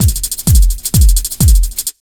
129BEAT1 4-L.wav